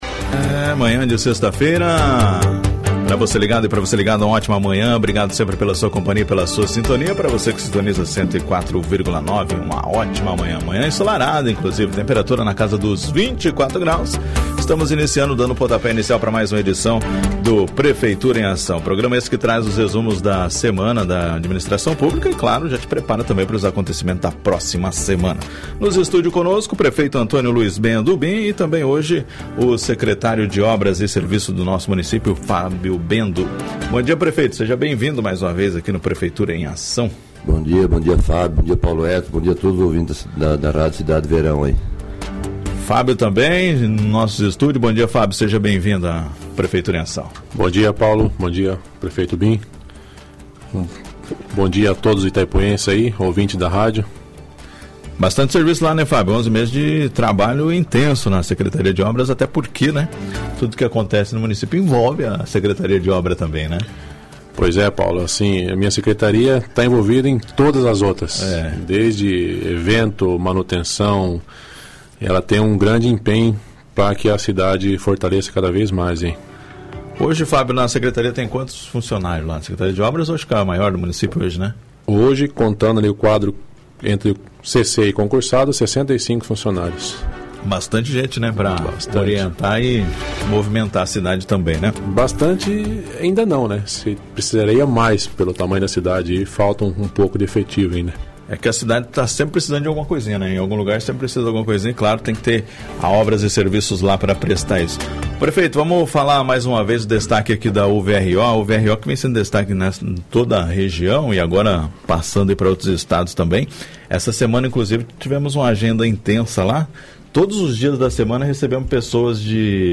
No programa Prefeitura em Ação desta sexta-feira, 14 de novembro de 2025, o prefeito Antonio Luiz Bendo (Bim) participou de uma entrevista especial, trazendo importantes informações sobre as ações em andamento no município. Durante sua participação, o prefeito destacou os avanços, investimentos e projetos que estão sendo executados para melhorar a qualidade de vida da população.
Também esteve presente o secretário de Obras, Fábio Bendo, que apresentou um panorama detalhado das atividades da pasta. Ele compartilhou informações necessárias sobre obras em execução, melhorias na infraestrutura e serviços que estão sendo desenvolvidos para atender as demandas da comunidade.